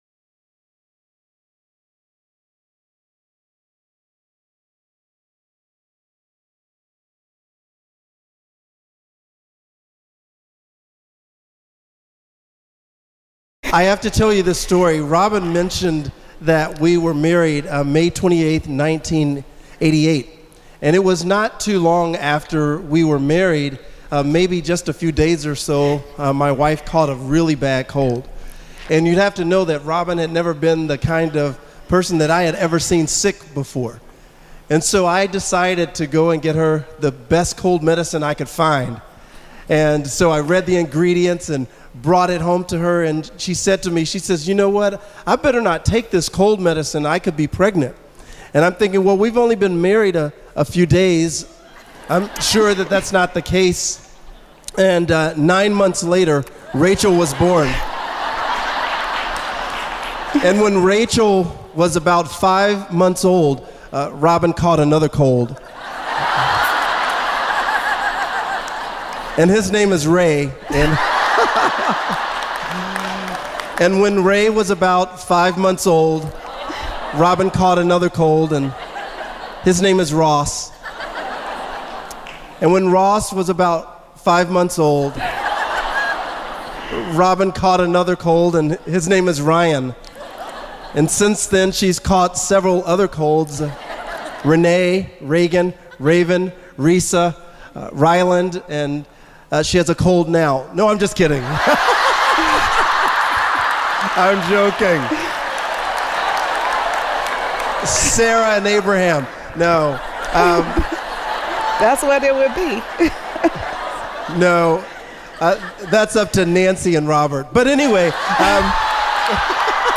This mom of ten offers an inspiring message from Psalm 127 reminding us that children are valuable assets and delightful gifts from God.